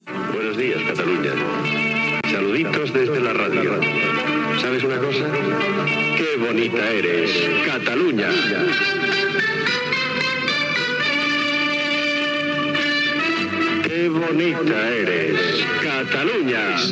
Salutació i esment a "Qué bonita eres, Cataluña"
Entreteniment